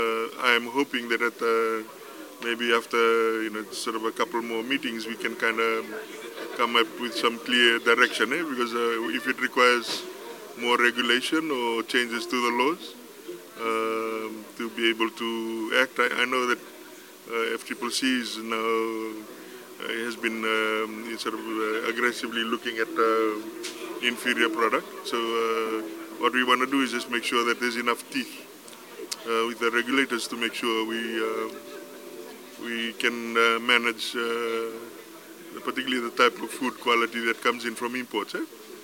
Trade Minister Manoa Kamikamica says the government aims to implement measures that will safeguard consumers from substandard products.